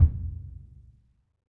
BDrumNewhit_v2_rr1_Sum.wav